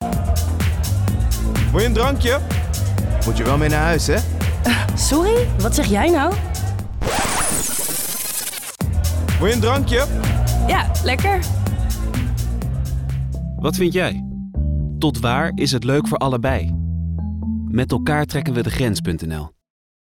Je hoort een bandje dat terugspoelt.